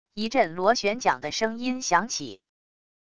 一阵螺旋桨的声音响起wav音频